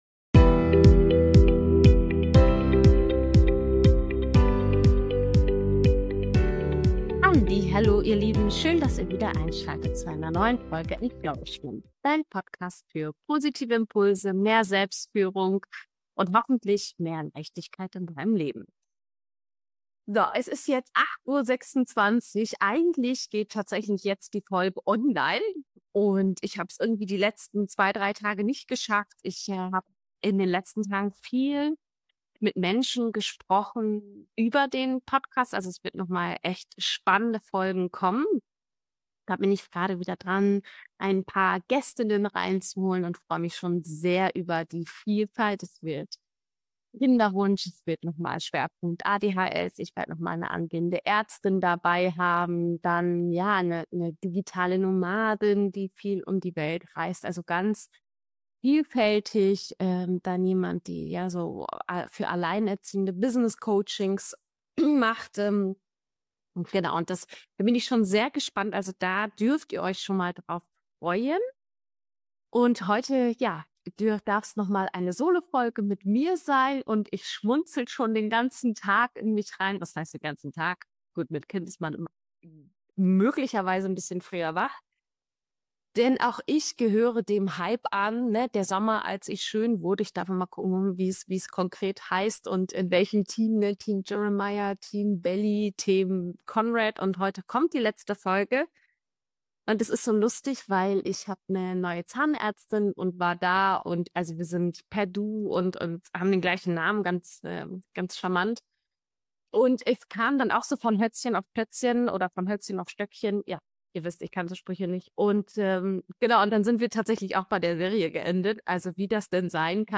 In dieser Solo-Folge spreche ich offen über Veränderung, Vereinbarkeit und was Erfolg für mich heute bedeutet. Es geht um Mut, Tempo rausnehmen und die Freiheit, eigene Wege zu gehen.